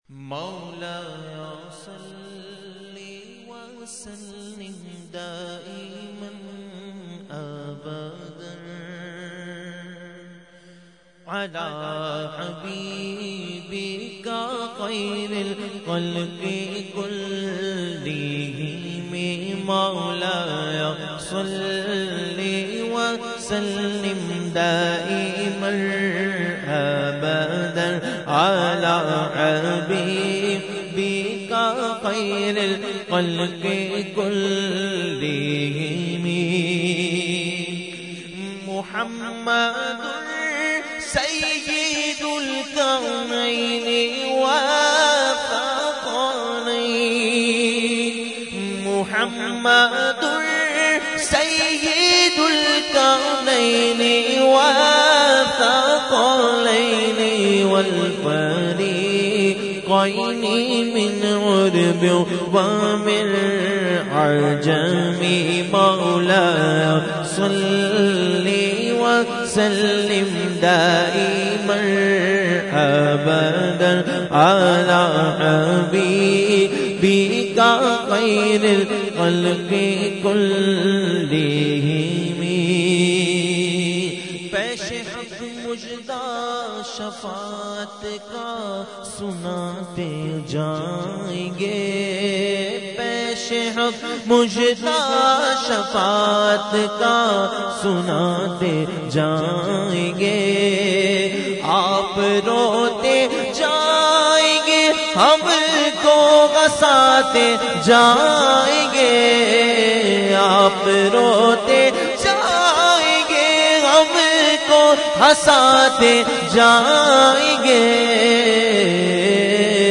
Mediaa: Mehfil 11veen Nazimabad 23 March 2012